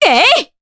Juno-Vox_Attack1_jp.wav